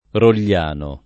Rogliano [ rol’l’ # no ]